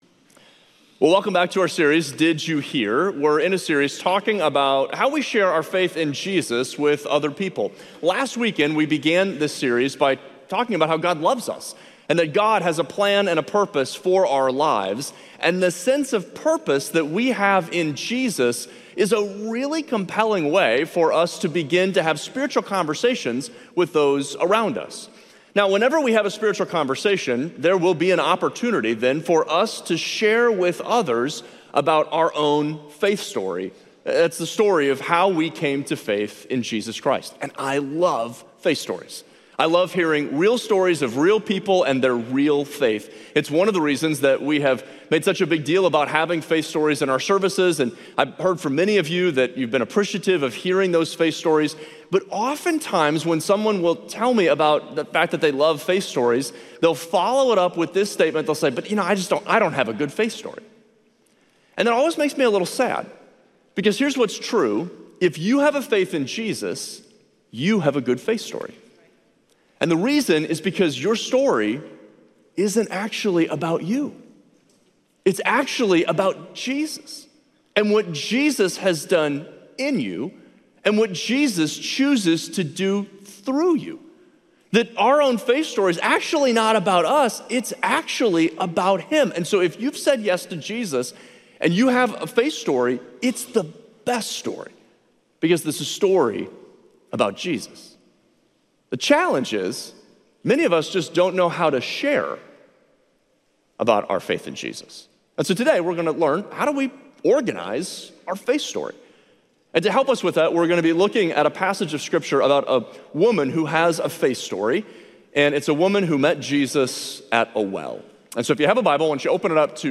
Share this Sermon Facebook Twitter Previous Jesus Gave Me a Purpose Next I was Blind, Now I See More from Series June 29, 2025 Series: Did You Hear?